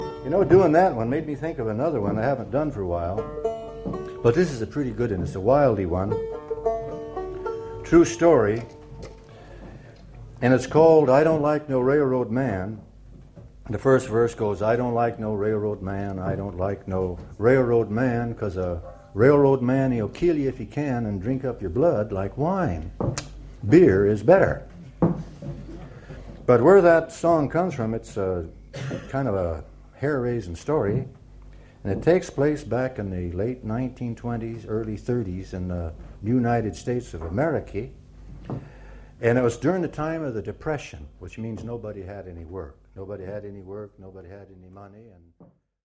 Live in Haarlem 1977
live_in_haarlem_intro_i_dont_like_no_railroad_man.mp3